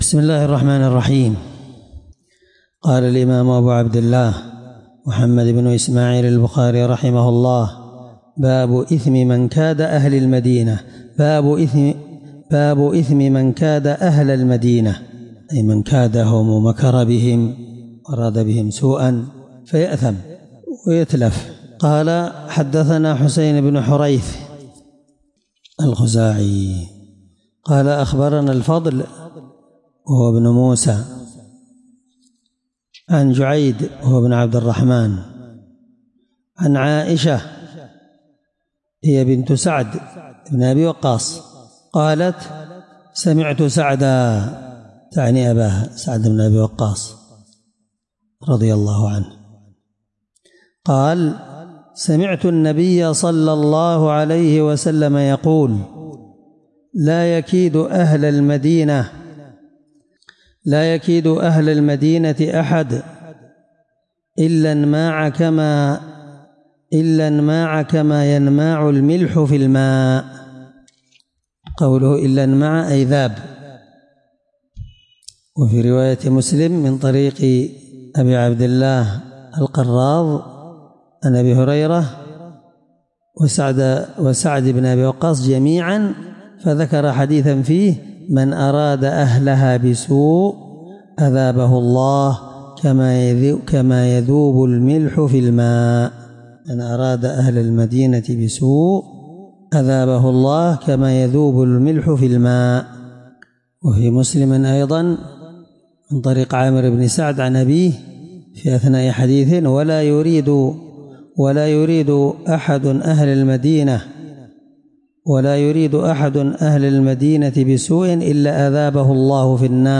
الدرس8من شرح كتاب فضائل المدينة حديث رقم(1877 )من صحيح البخاري